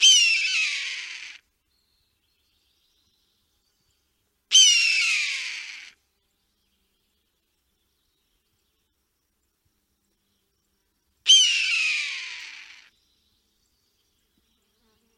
Звуки ястреба
Краснохвостый ястреб